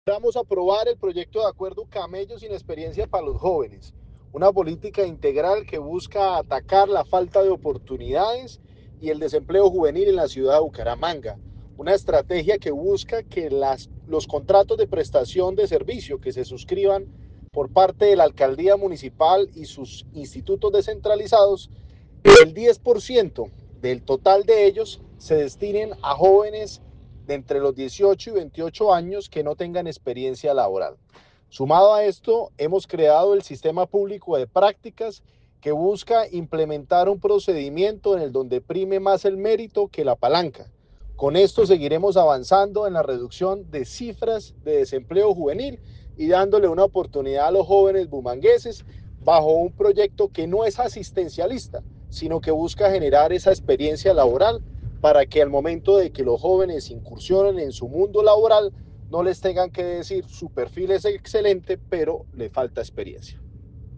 Diego Lozada, concejal de Bucaramanga